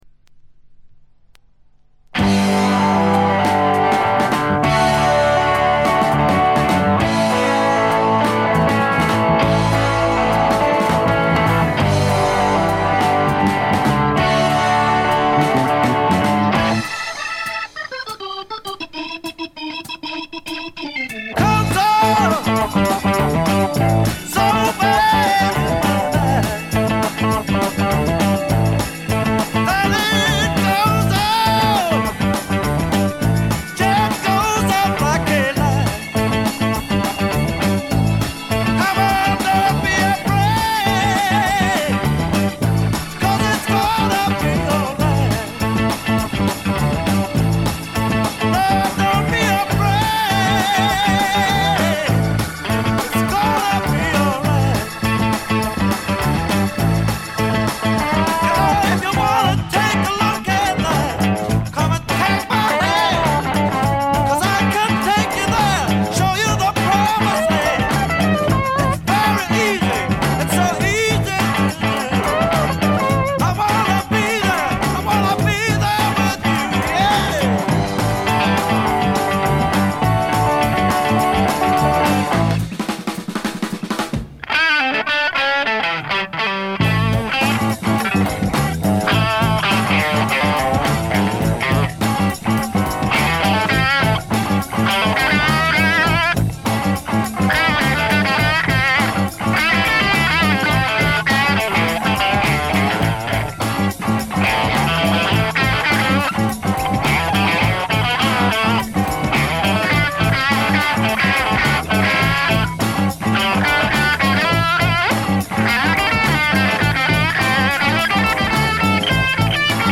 静音部でのわずかなノイズ感程度。
試聴曲は現品からの取り込み音源です。